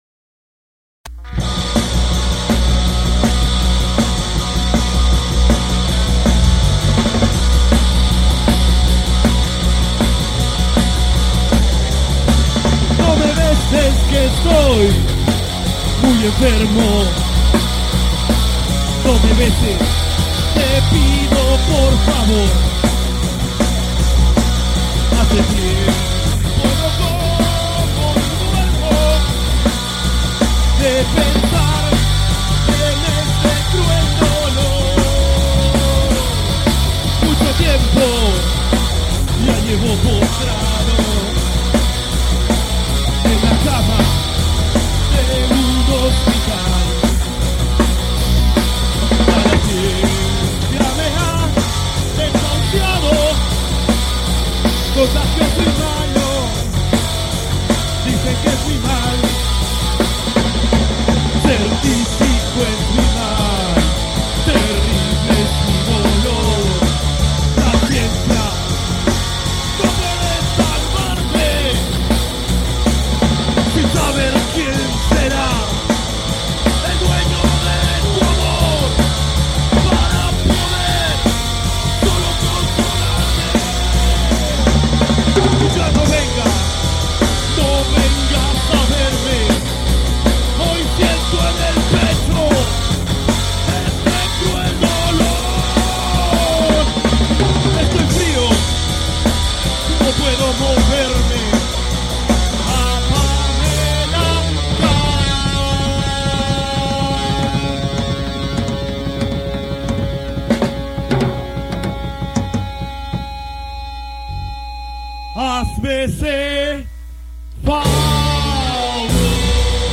##: grabación de concierto
cover del criollazo